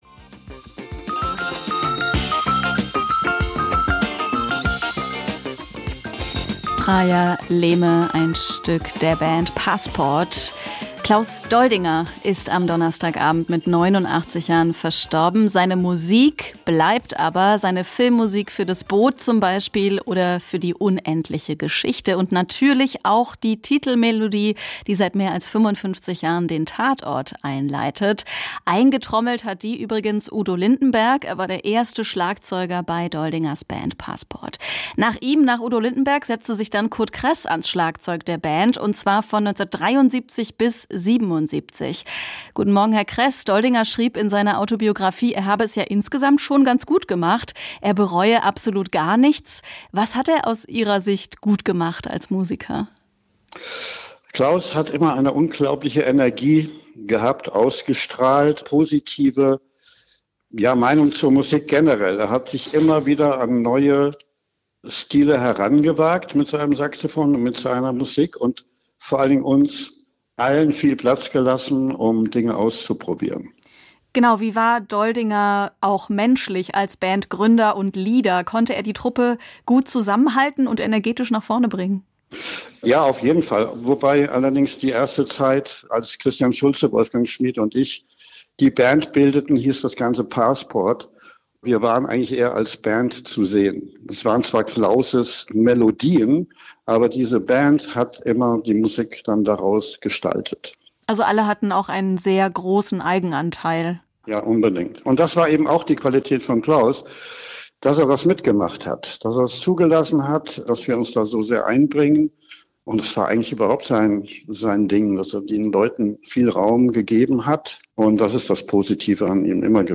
Passport-Schlagzeuger Curt Cress erinnert an Doldinger: „Klaus war immer in der Musik“
Interview mit